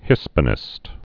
(hĭspə-nĭst)